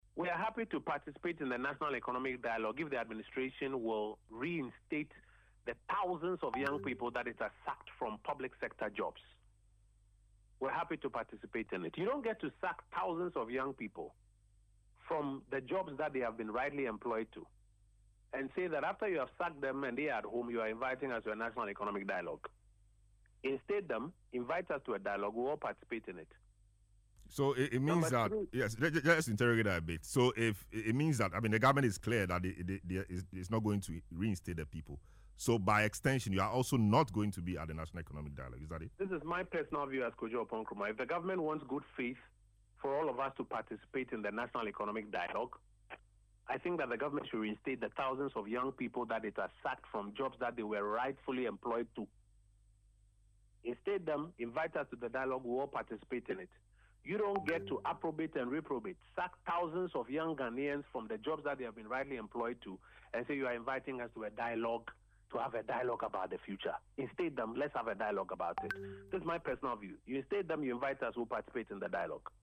“You don’t get to sack young people from the jobs that they have been rightly employed to, and say that after you had sacked them, and you are home, you are inviting us to a national economic dialogue. Reinstate them, invite us to a national economic dialogue, and we will participate in it,” he stated in an interview on Accra-based Citi FM.